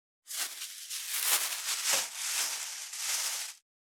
2025年3月2日 / 最終更新日時 : 2025年3月2日 cross 効果音
637スーパーの袋,袋,買い出しの音,ゴミ出しの音,袋を運ぶ音,